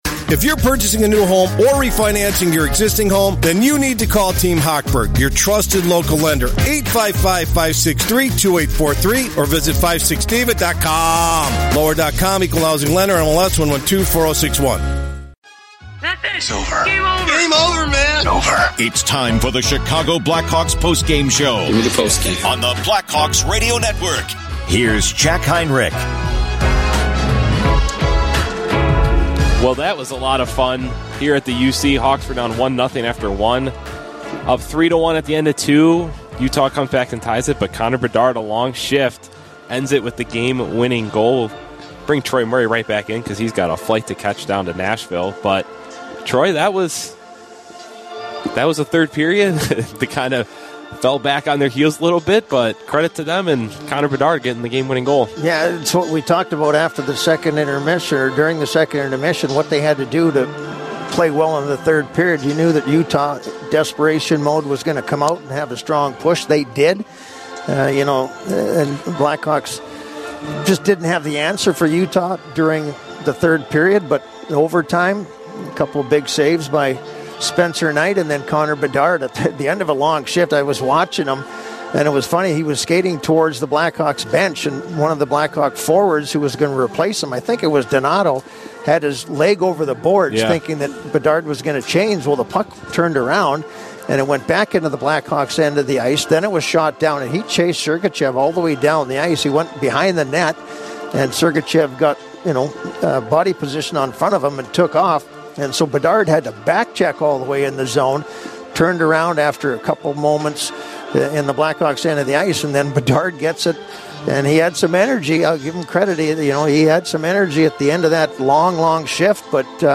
Later, hear postgame audio from Frank Nazar and Connor Bedard.